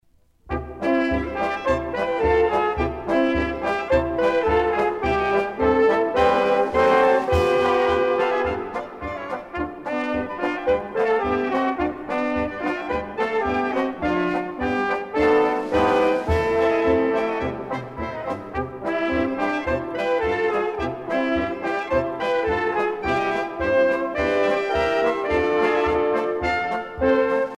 gestuel : à marcher
Pièce musicale éditée